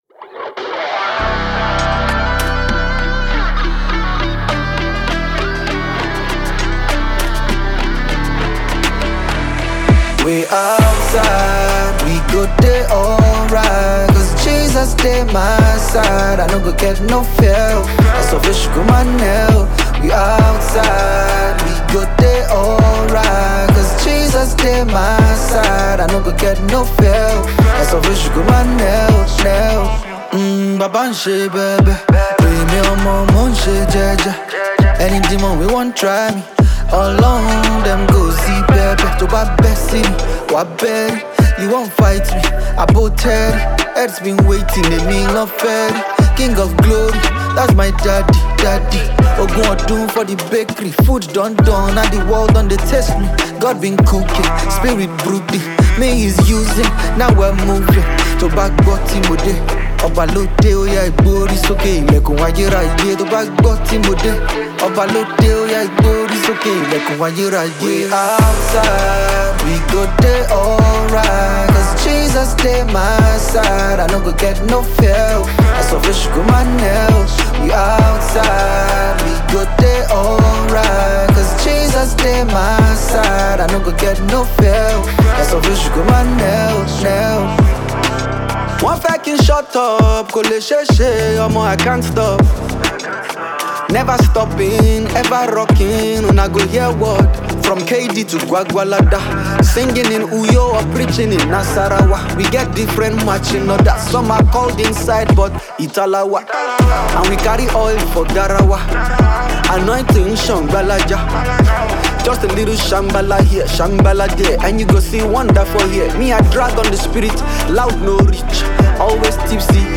Nigerian Afro-fusion artist